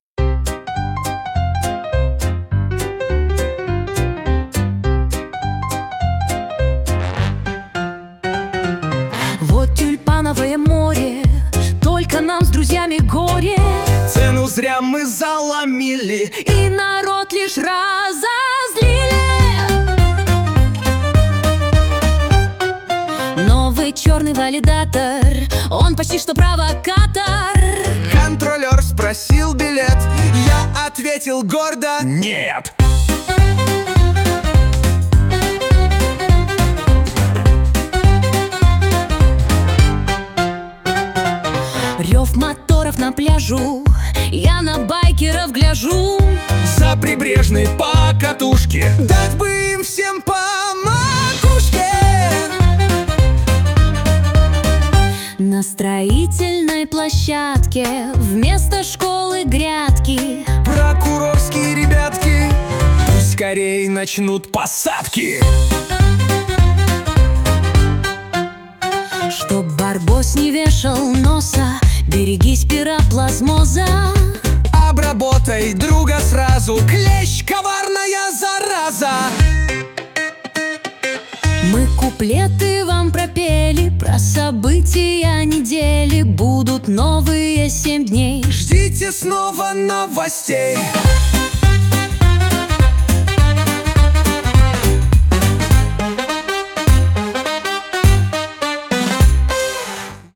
— музыкальный дайджест новостей недели в Калининграде (видео)
Самые заметные и важные события — в стиле куплетов